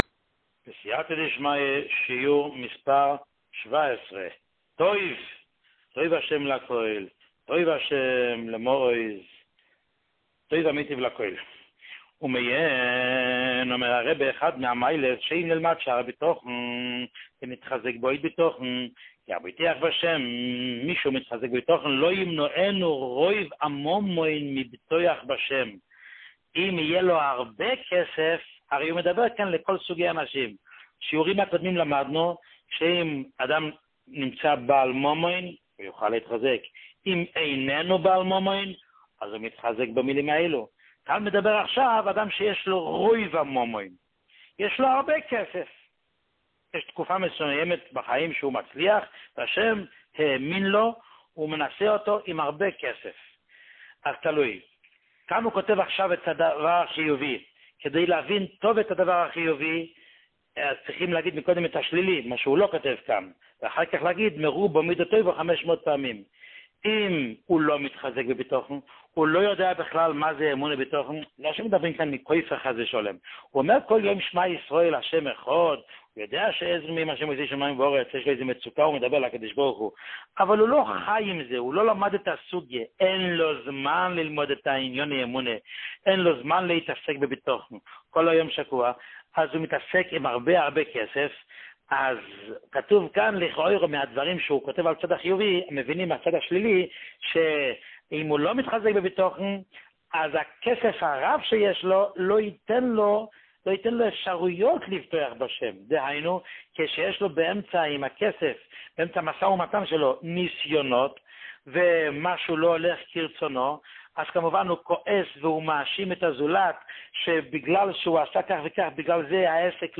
שיעורים מיוחדים
שיעור 17